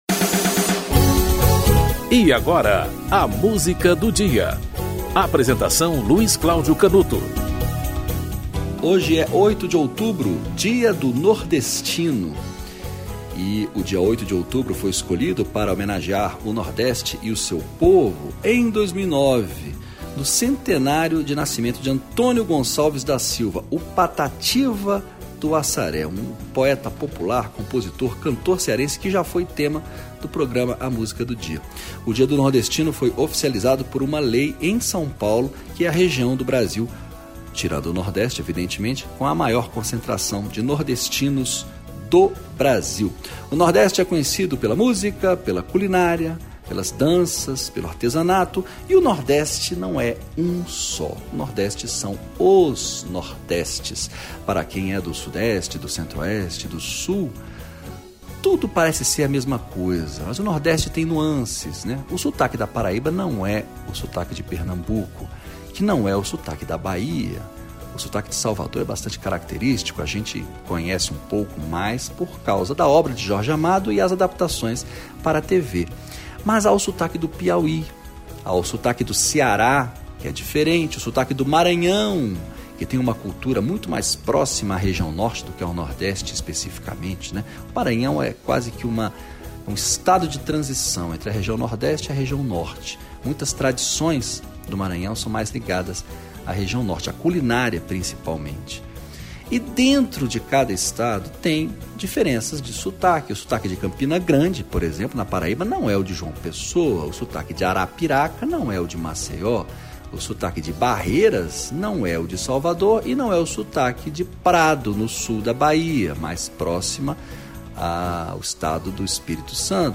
Produção e apresentação-